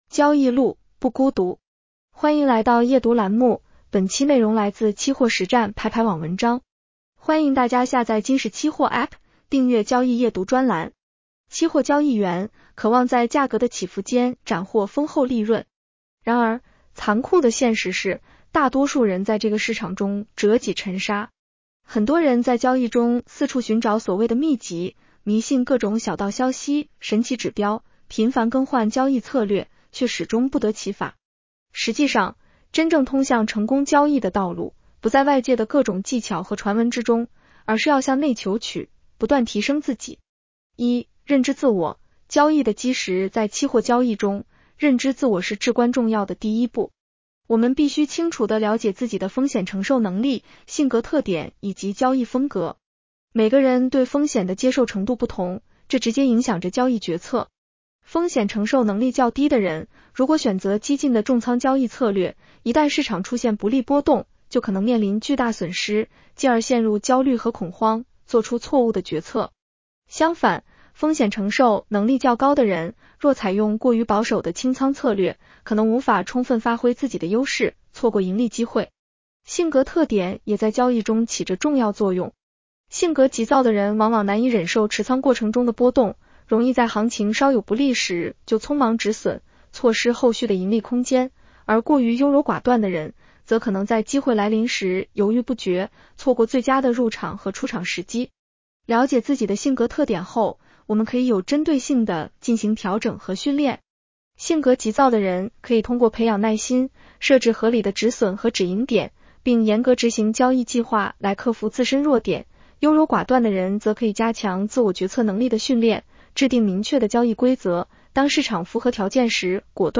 女声普通话版 下载mp3 期货交易员，渴望在价格的起伏间斩获丰厚利润。